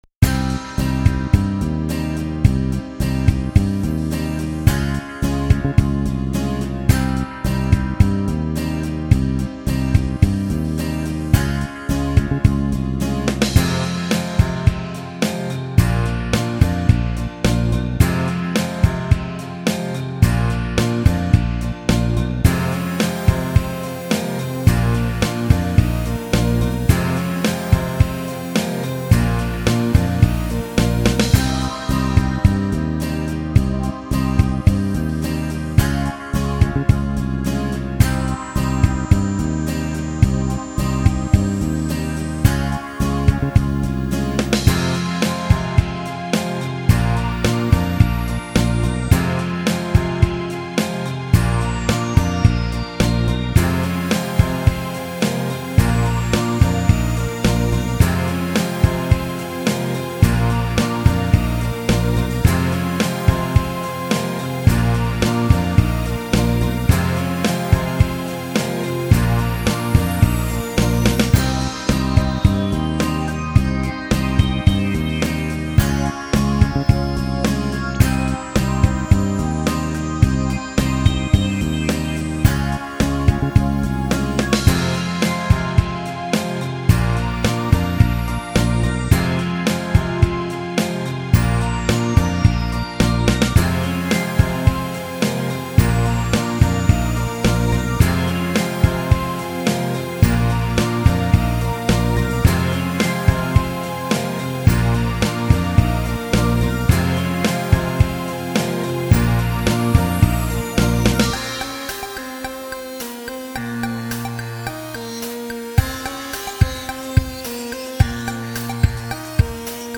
C'est une popsong moitié acoustique, moitié electronique dans sa version instrumentale en attendant la version complète.
Je savais pas quoi mettre comme style musical, alors j'ai mis pop general.
Automatic_instrumentalversion.MP3